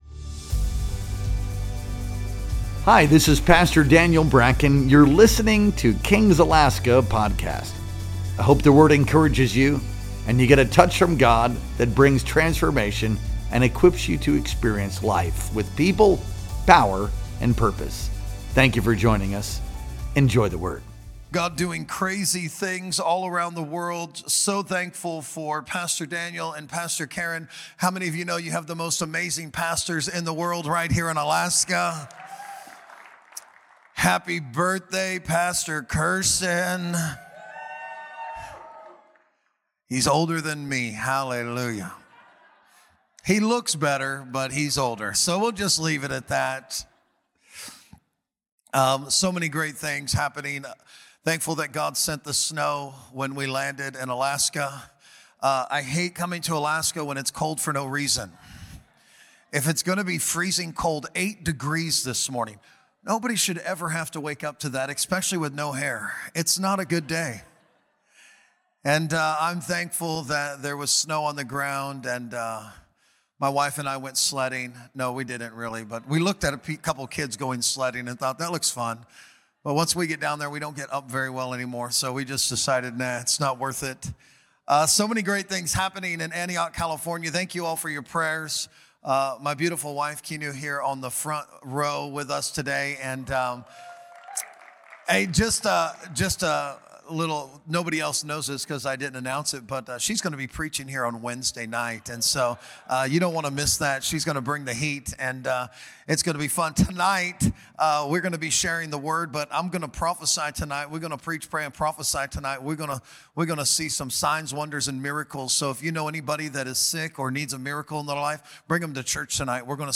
Our Sunday Morning Worship Experience streamed live on November 9th, 2025.